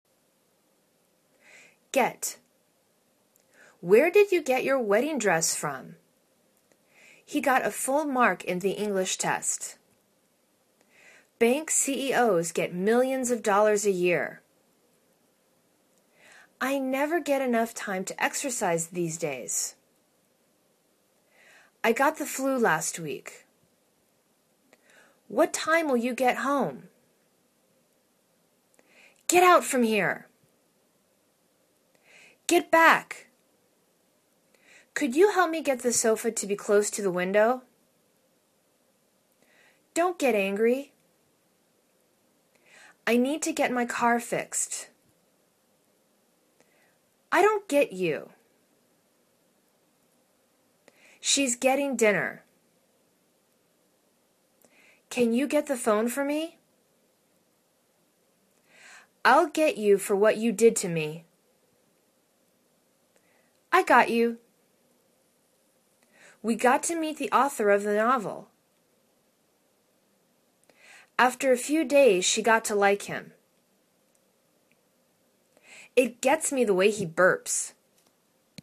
get     /get/    v